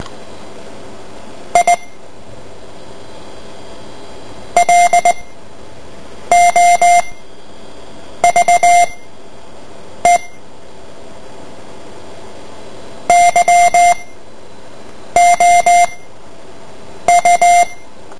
Soubor:I love you morse code.ogg
I_love_you_morse_code.ogg